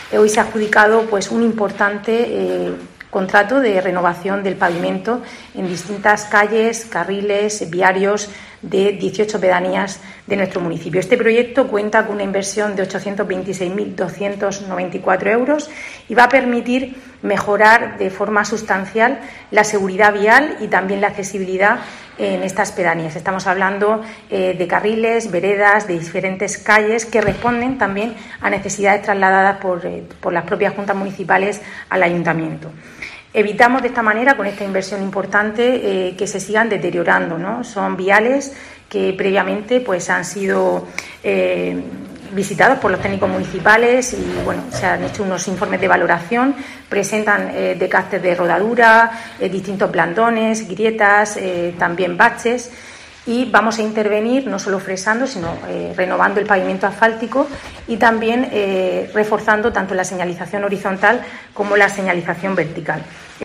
Rebeca Pérez, vicealcaldesa de Murcia
Según ha explicado Pérez en rueda de prensa, los trabajos se iniciarán de forma inminente y tendrán un plazo de ejecución de tres meses.